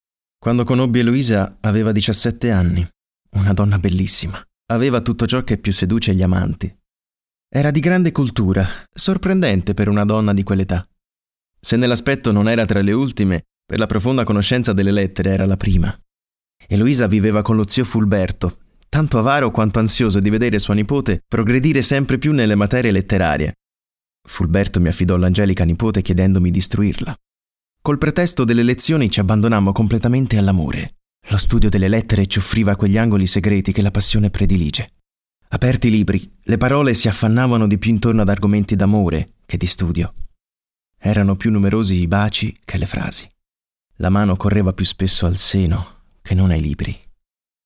Italian male voice, Voce italiana, italian speaker, dubbing, doppiaggio italiano, e-learning italiano.
Sprechprobe: eLearning (Muttersprache):
My voice is clear, deep and i can change it as you want for commercials, e-learning, cartoon and corporate.